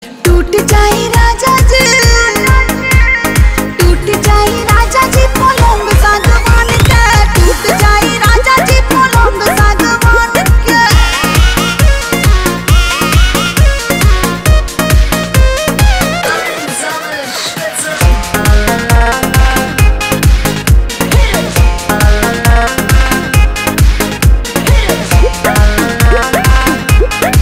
Bhojpuri Songs
High-Quality Free Ringtone